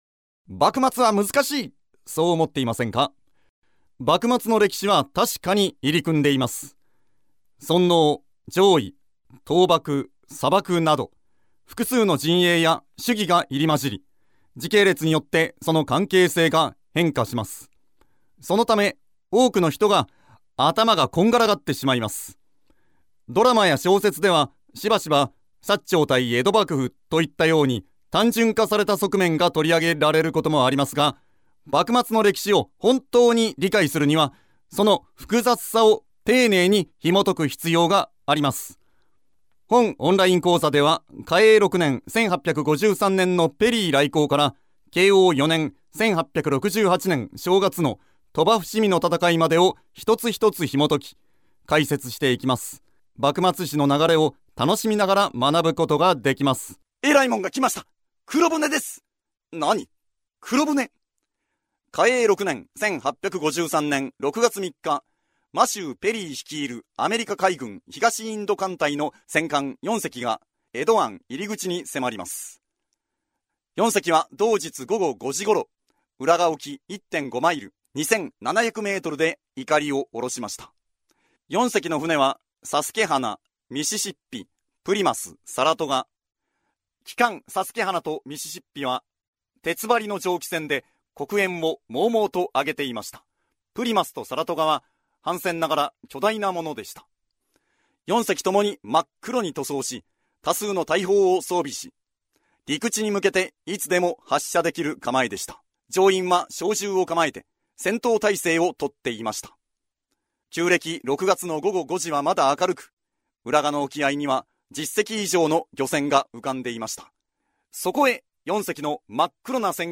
楽しく躍動感ある語りで好評をはくす。